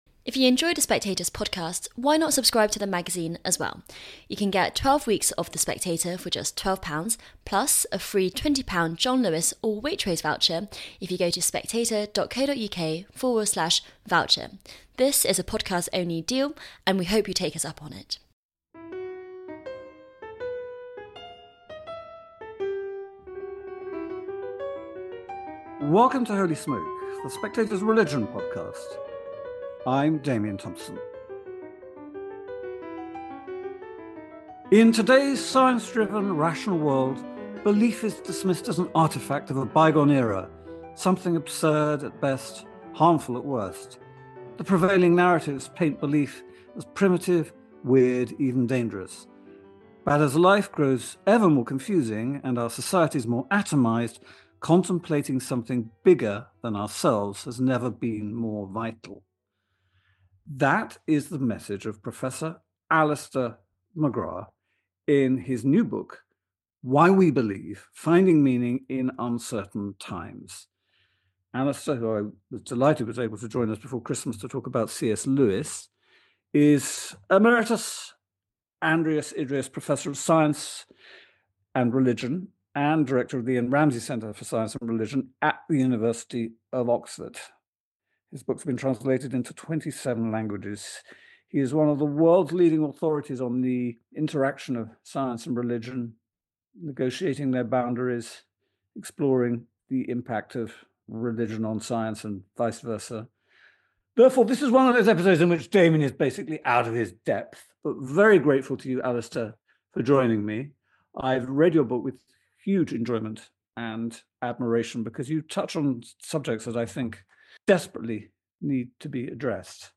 Why militant atheists don’t understand religion: a conversation with Alister McGrath